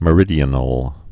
(mə-rĭdē-ə-nəl)